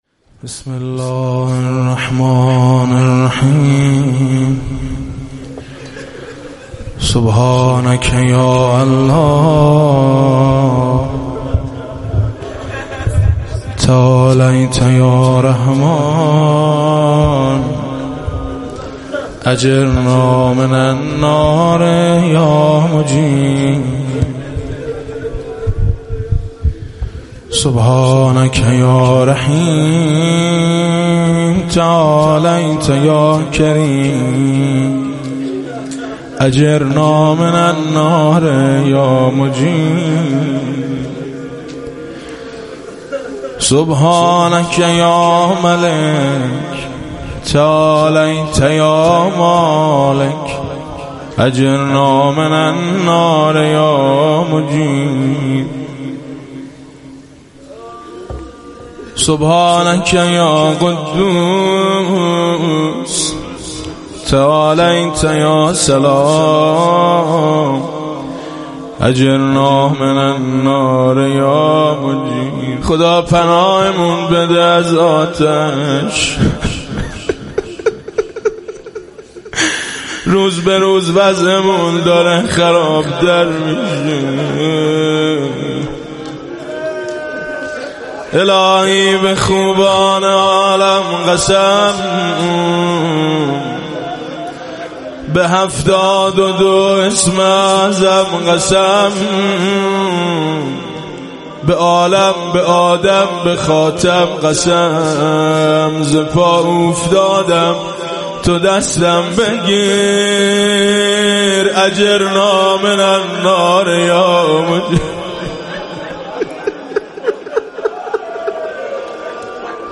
صوت/ "دعای مجیر" با نوای میثم مطیعی